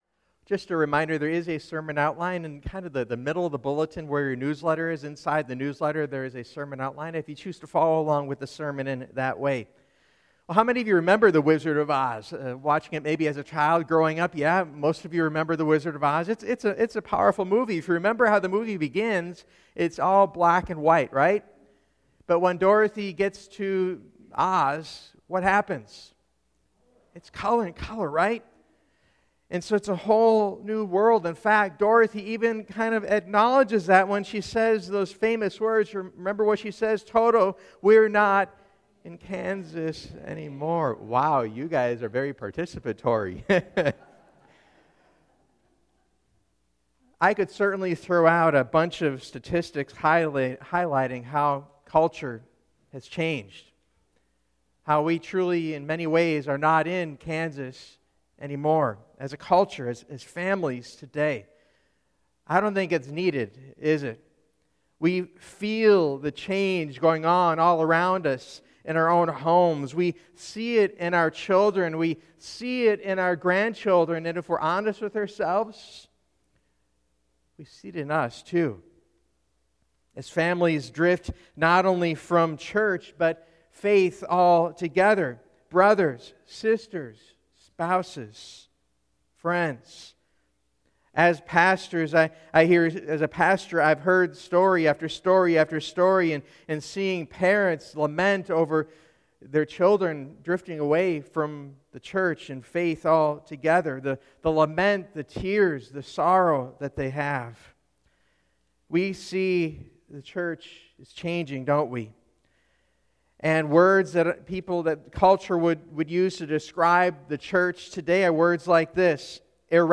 Here are the upcoming sermons in the series: “A New Beginning” “A Renewed Family” “A Renewed Mission”